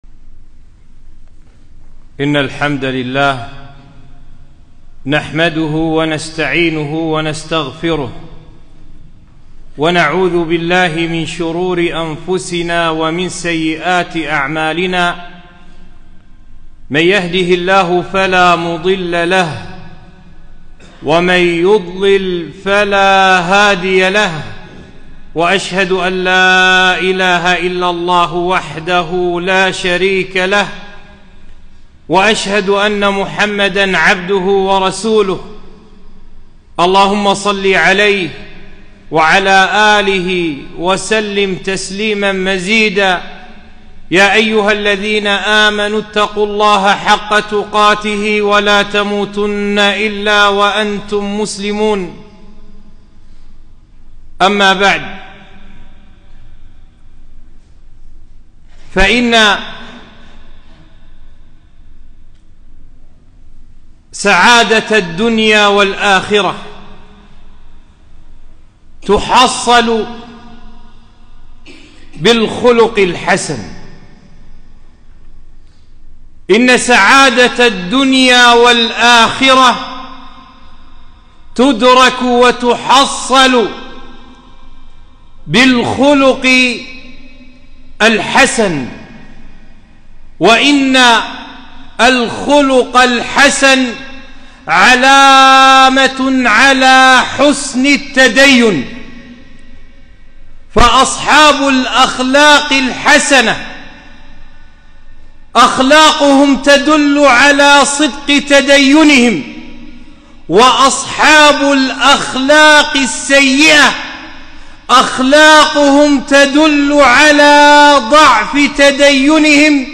خطبة - حسن الخلق